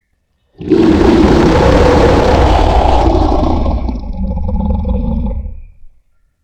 Dragon Roar Sfx Bouton sonore